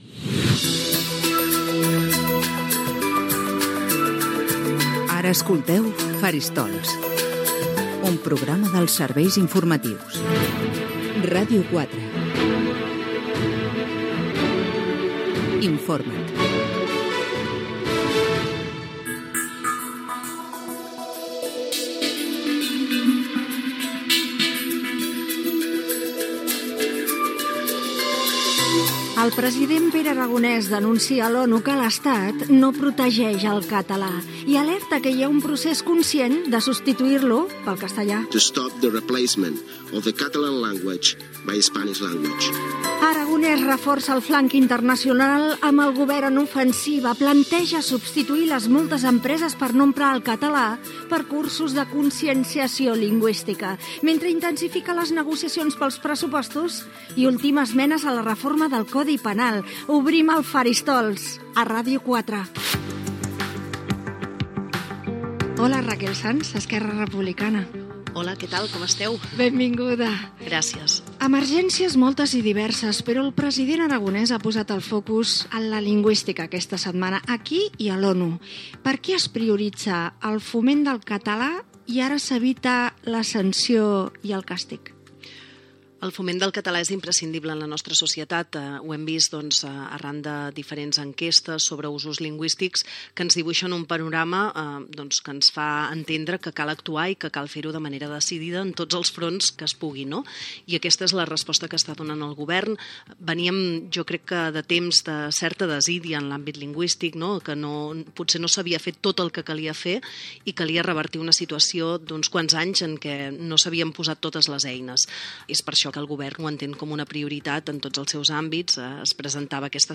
Indicatiu del programa, presentació amb els titulars de la setmana al Parlament de Catalunya, identificació, entrevista a Raquel Sans d'Esquerra Republicana sobre el foment de l'ús del català
Informatiu
FM